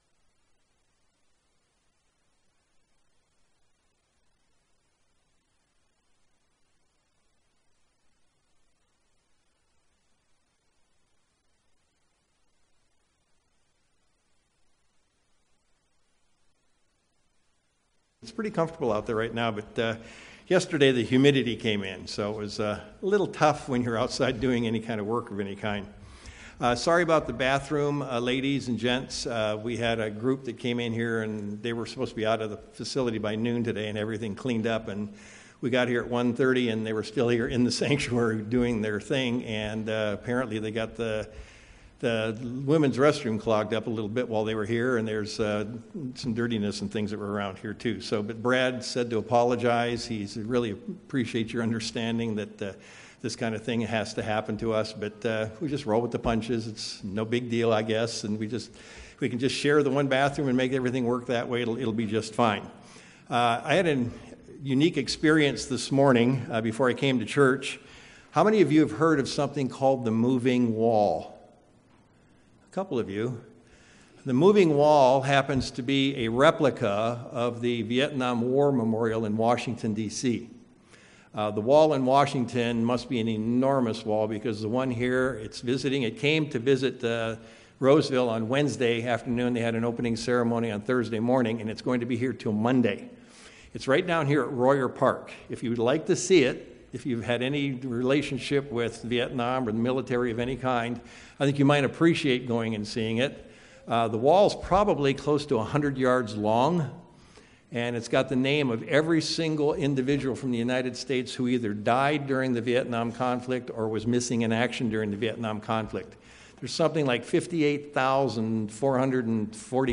Given in Sacramento, CA
View on YouTube UCG Sermon Studying the bible?